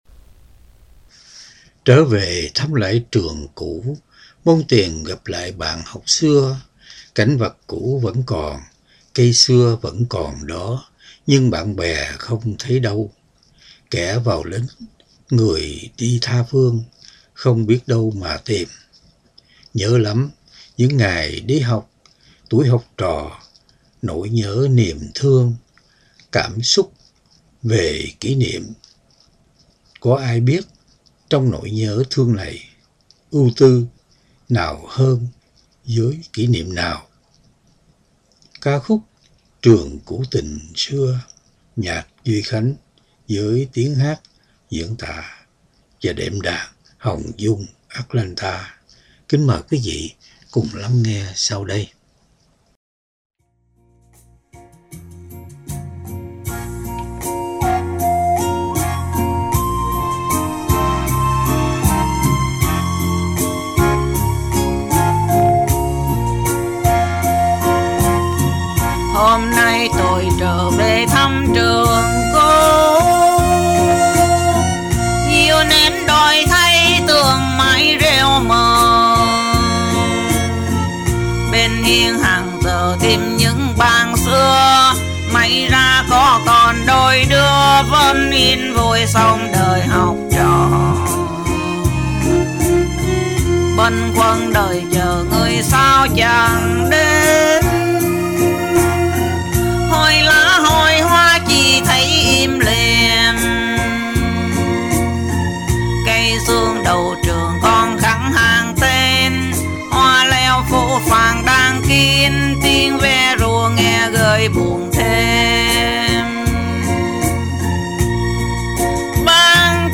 Đệm Đàn